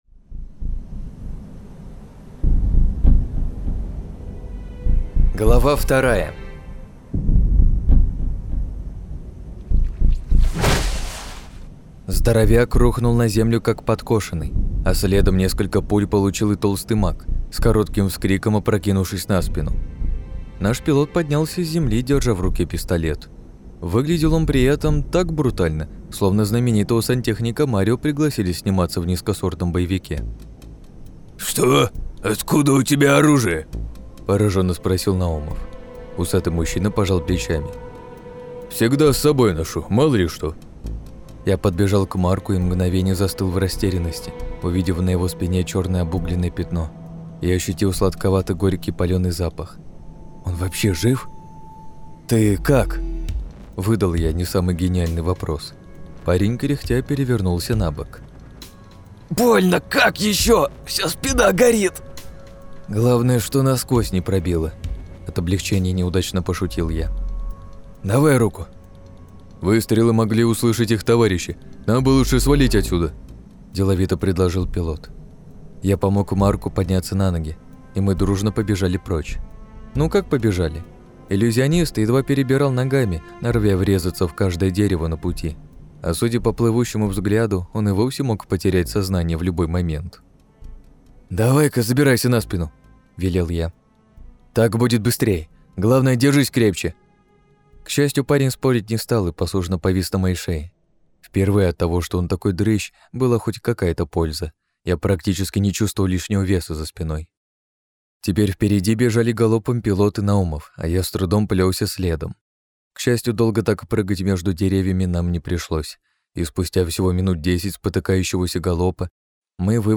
Аудиокнига - слушать онлайн